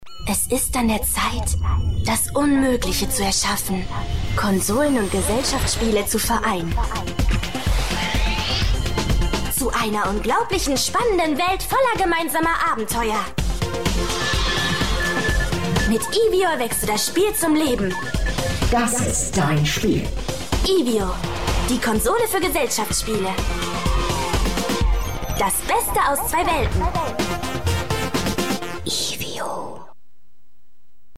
junge aufstrebende Sprecherin
Sprechprobe: Werbung (Muttersprache):